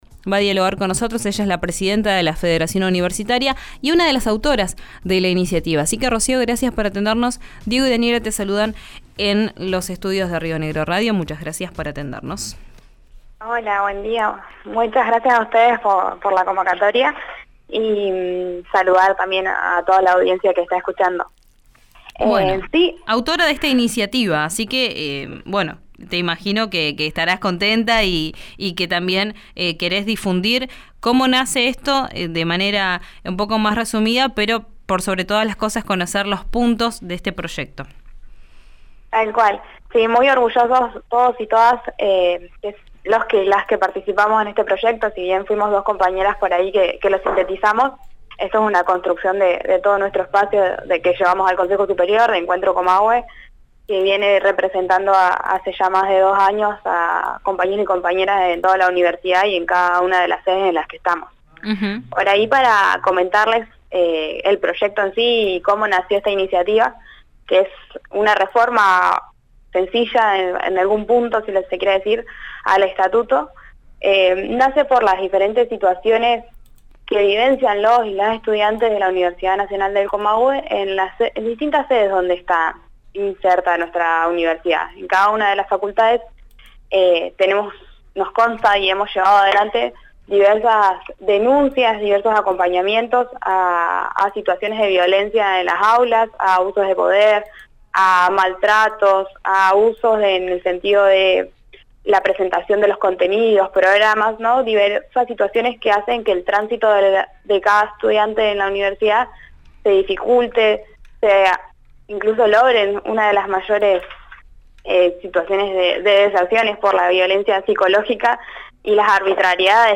En diálogo con RÍO NEGRO RADIO, la referente explicó que la iniciativa nace de un «reforma sencilla» de una ordenanza de la universidad, «la 485 del año 91, que regula el sistema único de planificación y evaluación de actividades académicas para los docentes, que habla precisamente de encuestas obligatorias de los estudiantes».